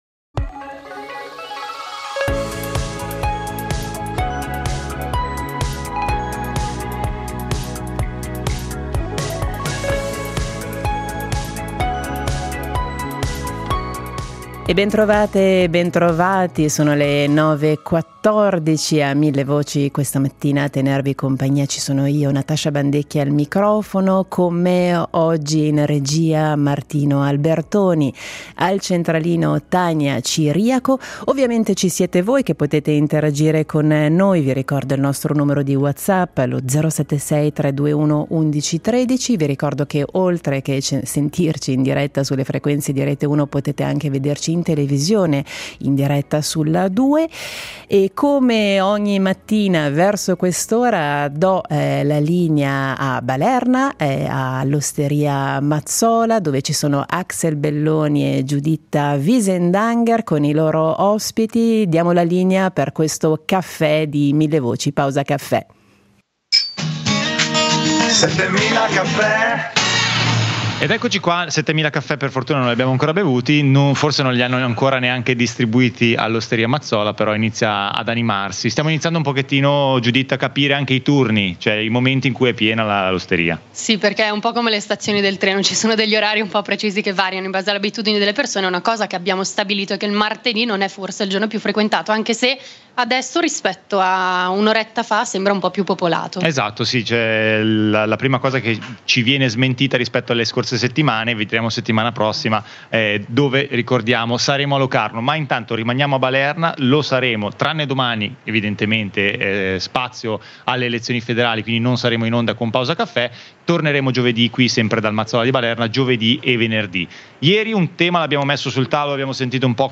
Ogni giorno, dal lunedi al venerdi, vi porteremo in un bar della regione per conoscere il territorio, viverlo da vicino, scoprire storie e commentare notizie curiose in compagnia.